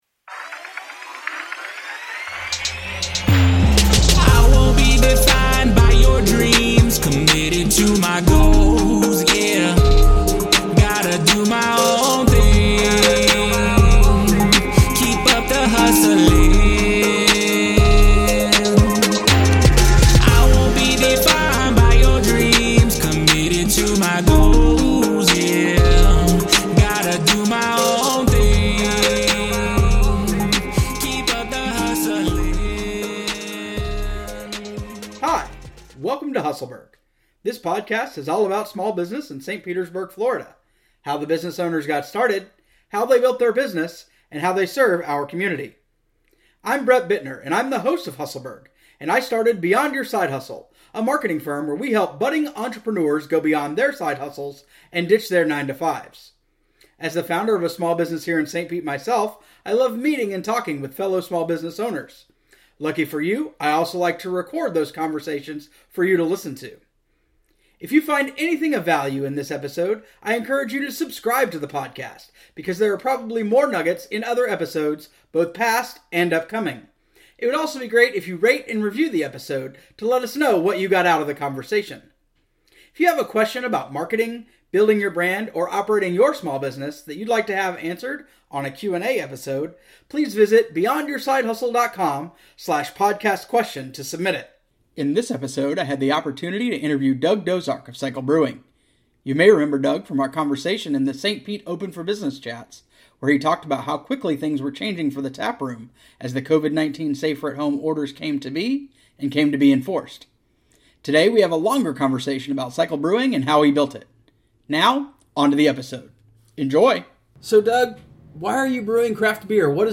Hustleburg is a podcast, featuring small businesses in Saint Petersburg (and the rest of Pinellas County) discussing those businesses, how they were built, and what they do in the community. It is an interview-style show that also answers marketing and management questions from the audience and guests.